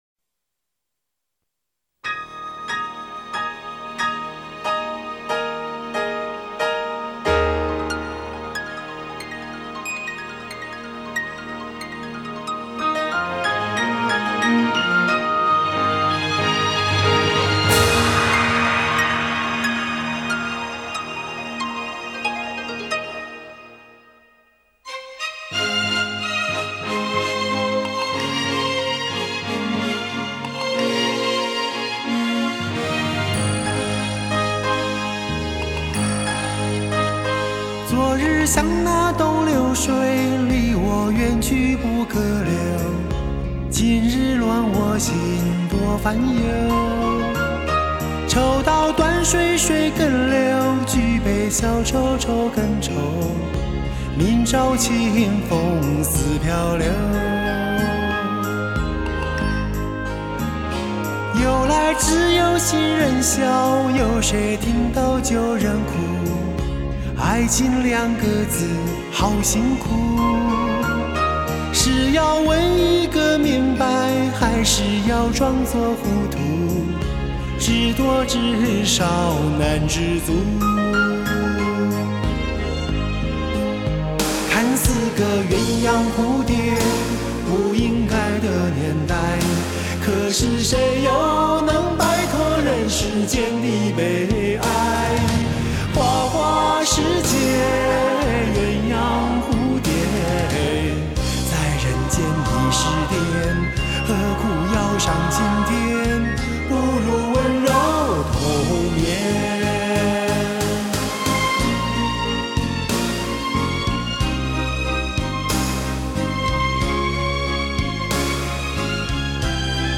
歌曲带有浓厚的中国风色彩，古诗词甚至佛经的引用使得歌词多了几分文化的韵味。